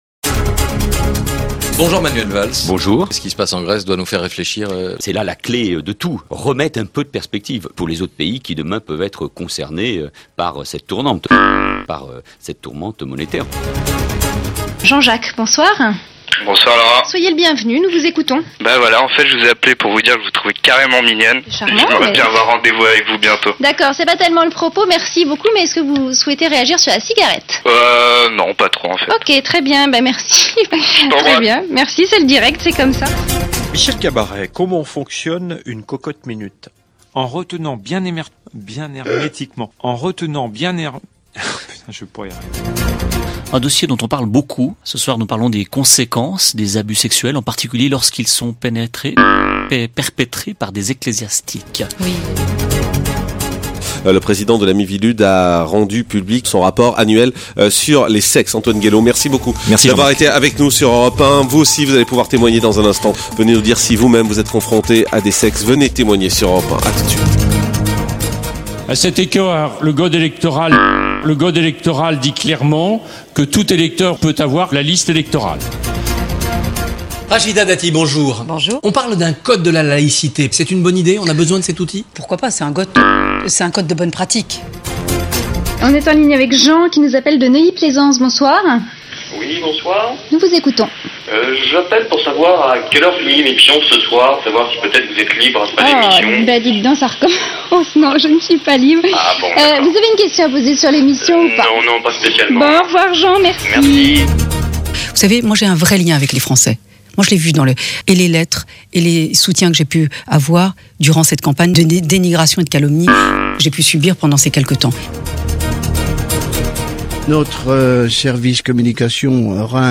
La dernière livraison de la saison comprend son lot habituel de lapsus, bafouillages, barbarismes et incidents du direct.
03. FRANCE BLEU ARMORIQUE BETISIER 2010 : Un animateur peine sur le mot "hermétiquement".